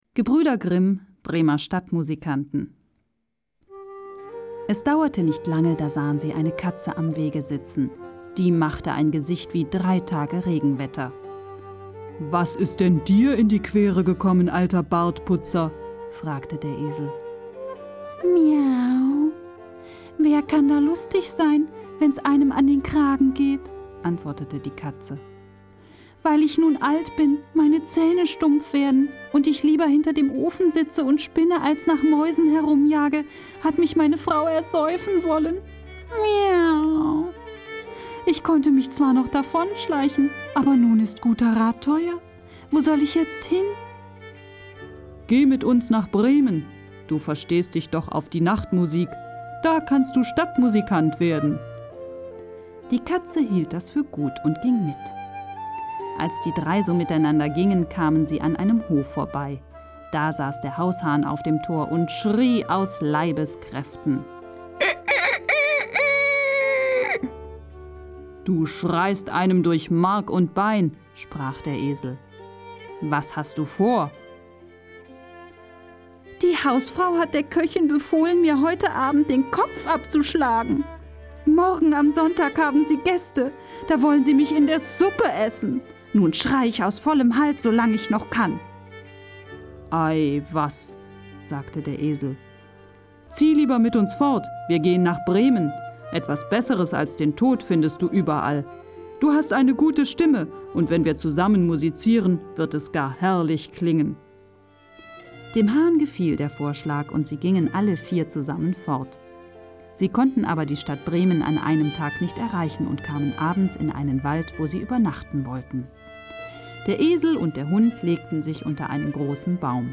Typ: warme Stimme, sehr wandlungsfähig: von seriös, frisch bis sexy.
Profi-Sprecherin.
Sprechprobe: Industrie (Muttersprache):